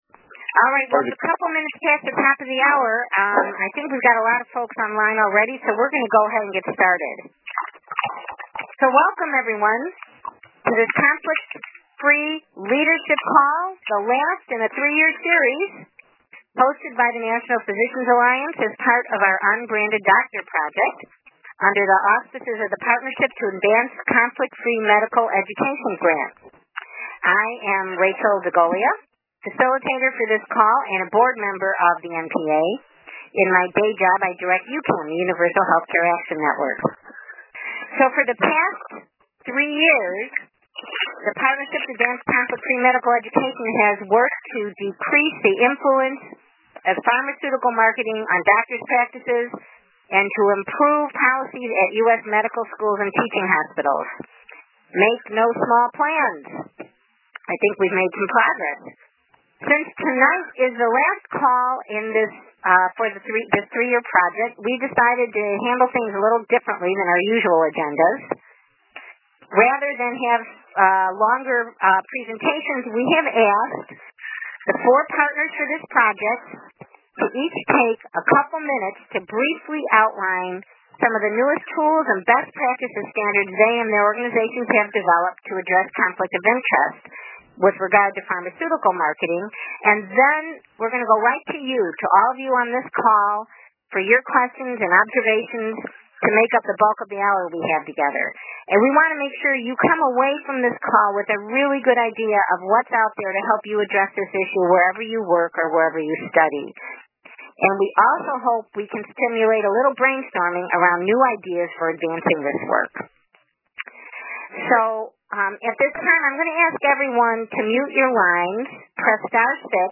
This lecture series highlights advocacy and policy experts who provide technical assistance and leadership development for physicians, residents, and medical students interested in conflict-of-interest reform efforts.